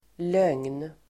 Uttal: [löng:n]